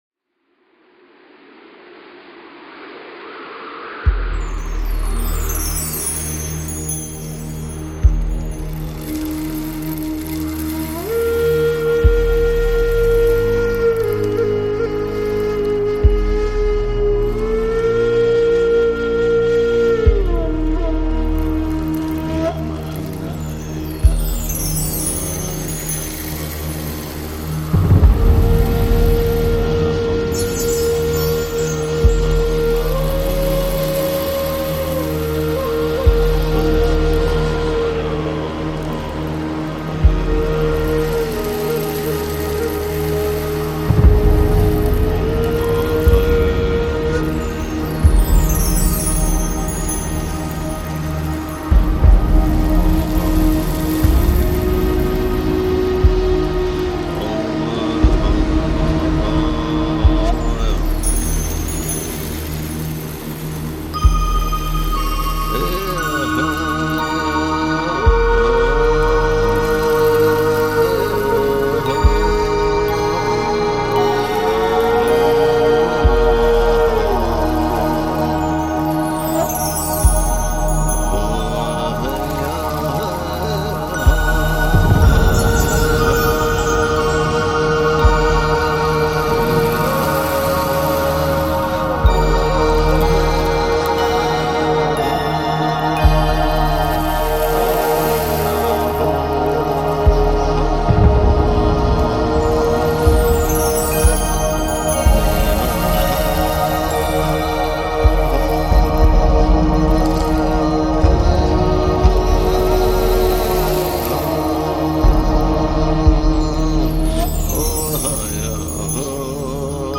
Méditation au tambour chamaniqe et la flute amérindienne
meditation-amerindienne-avec-chant-tambour-chamane-et-flute-amerindienne.mp3